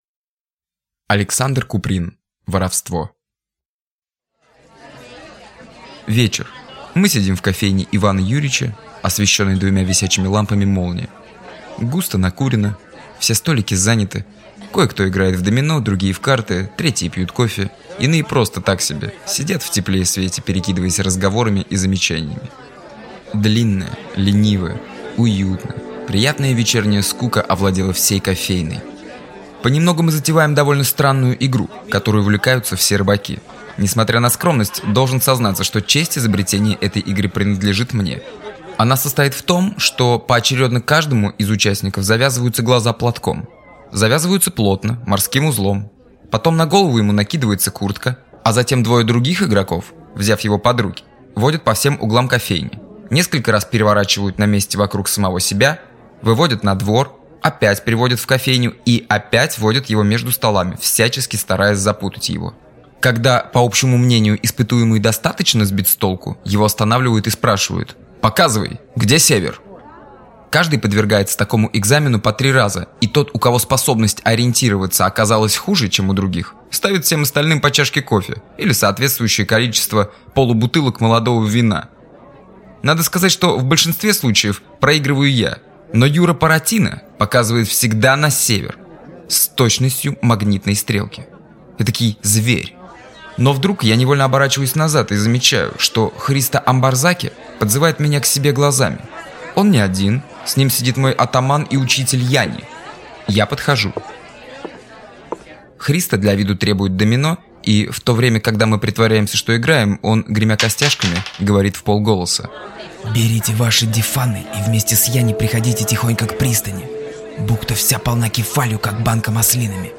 Аудиокнига Воровство | Библиотека аудиокниг